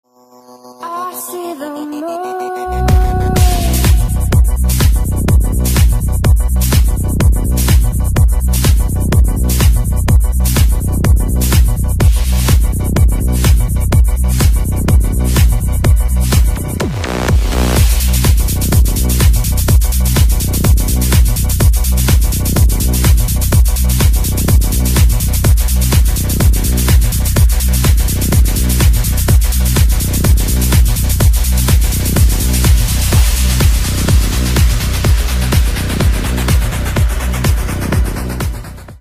Громкие Рингтоны С Басами
Рингтоны Электроника